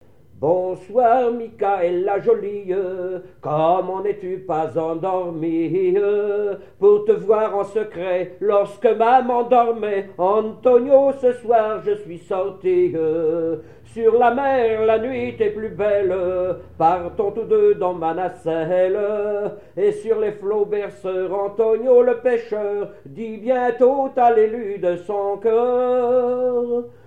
Mémoires et Patrimoines vivants - RaddO est une base de données d'archives iconographiques et sonores.
Genre strophique
Pièce musicale inédite